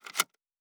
pgs/Assets/Audio/Sci-Fi Sounds/Mechanical/Device Toggle 15.wav at 7452e70b8c5ad2f7daae623e1a952eb18c9caab4
Device Toggle 15.wav